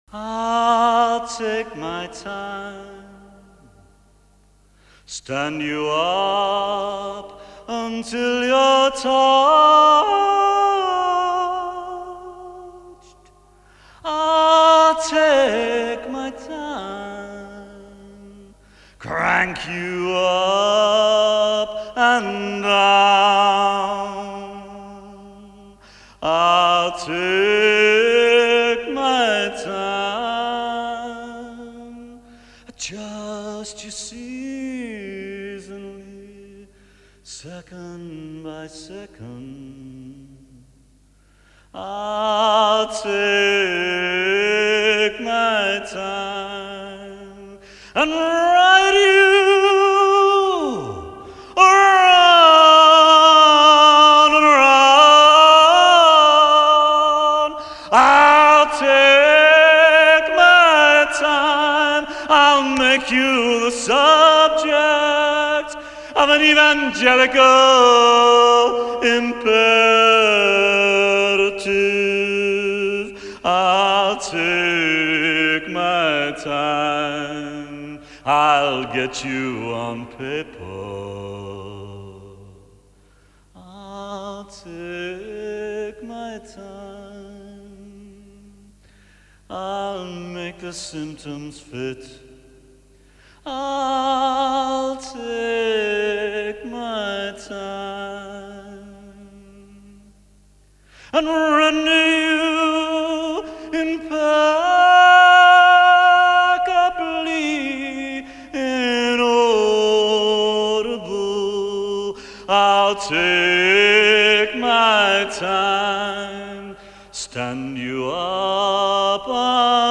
Genre: World Music